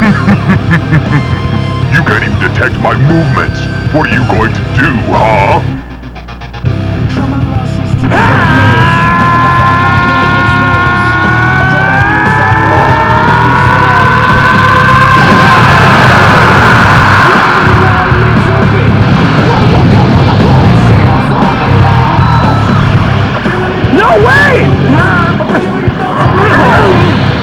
Cooler's Revenge GokuwCooler.wav -This is a sound with Cooler fighting Goku.